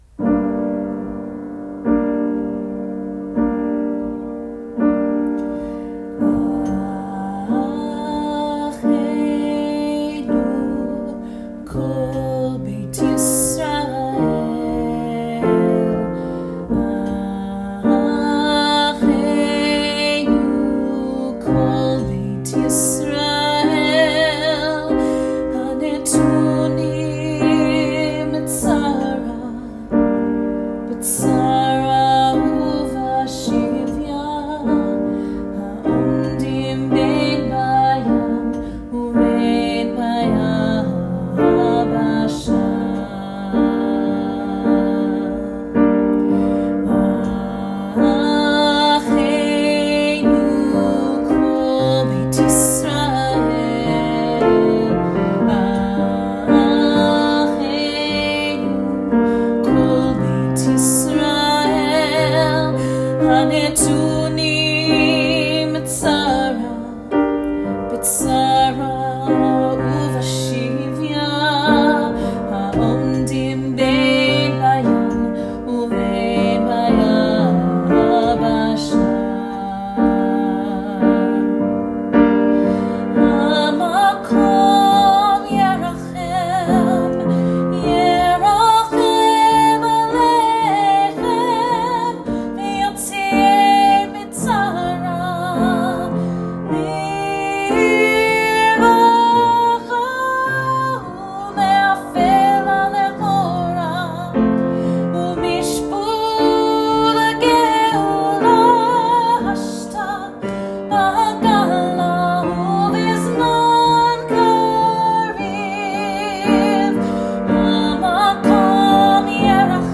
which we recorded last Pesach